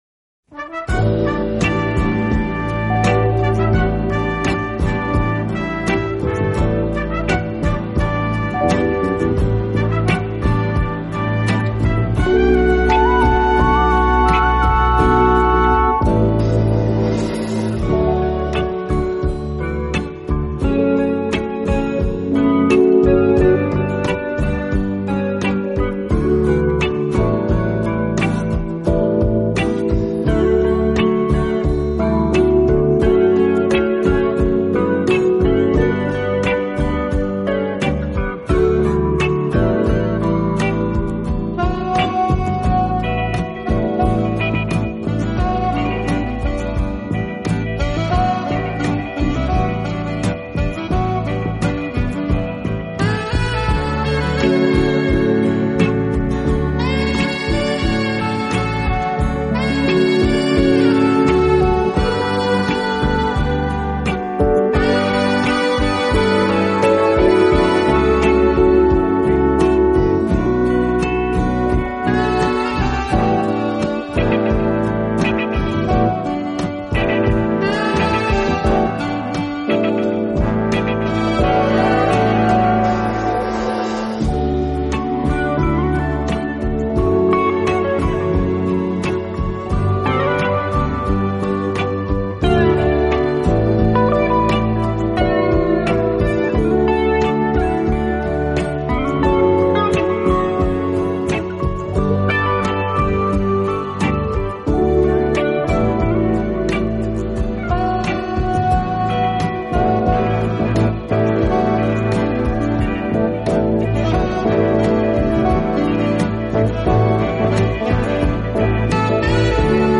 巨大力量，总的来说，它的柔和优美的音色，具有弦乐器的歌唱风格。